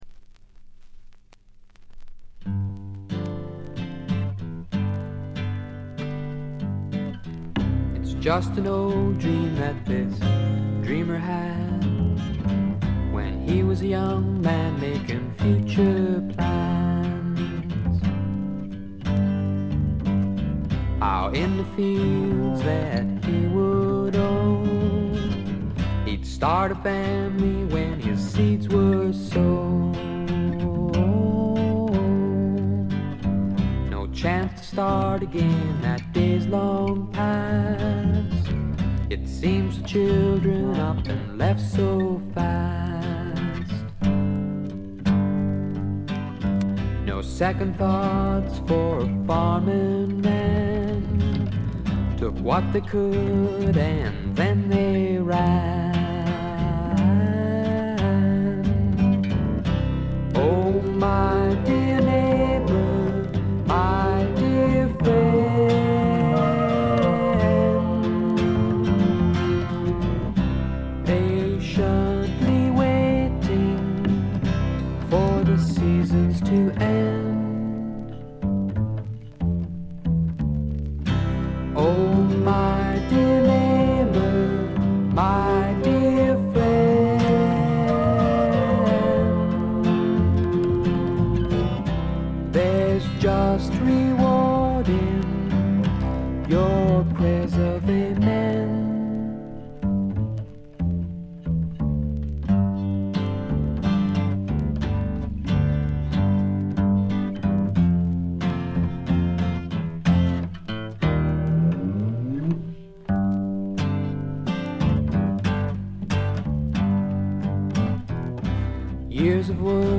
部分試聴ですが軽微なバックグラウンドノイズ程度。
試聴曲は現品からの取り込み音源です。